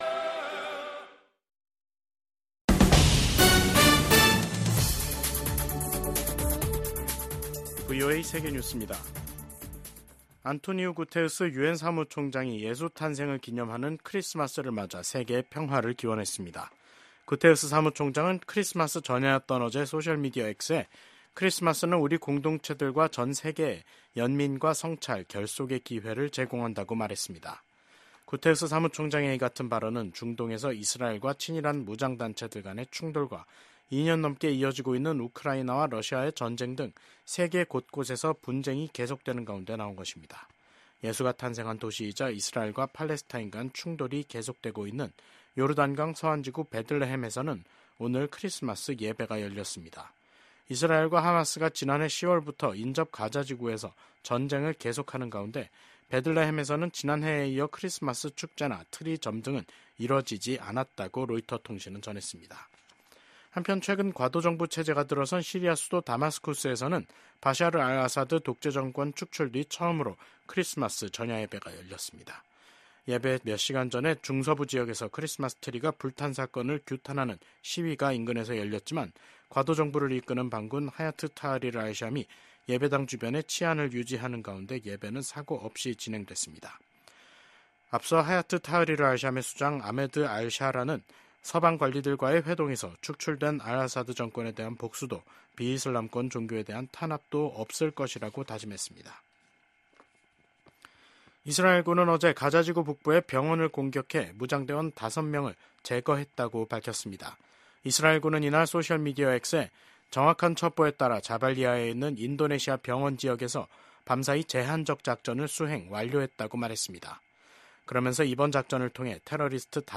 VOA 한국어 간판 뉴스 프로그램 '뉴스 투데이', 2024년 12월 25일 3부 방송입니다. 한국의 계엄과 탄핵 사태로 윤석열 대통령이 미국과 공조해 추진해 온 미한일 3국 협력이 지속 가능하지 않을 수 있다고 미 의회조사국이 평가했습니다. 미국과 한국 정부가 한국의 비상계엄 사태로 중단됐던 양국의 외교안보 공조 활동을 재개키로 했습니다. 일본에서 발생한 대규모 비트코인 도난 사건이 북한 해커집단의 소행으로 밝혀졌습니다.